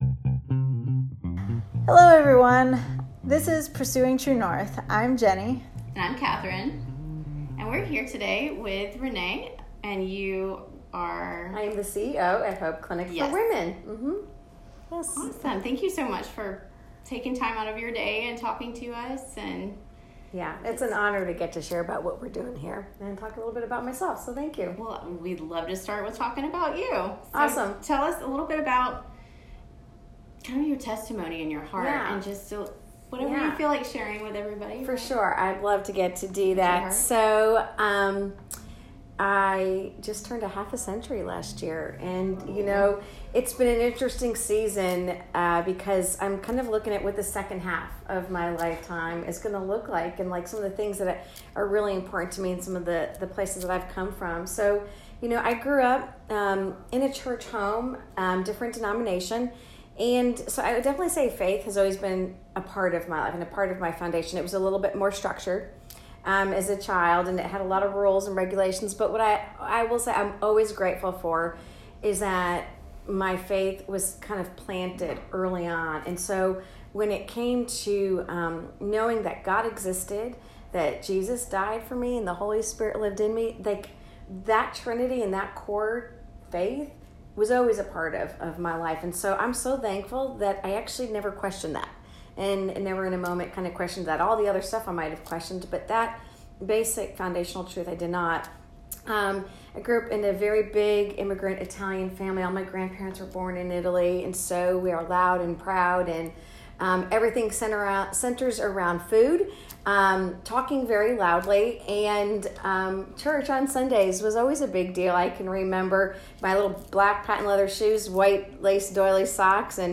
See how you can be involved at the end of our interview!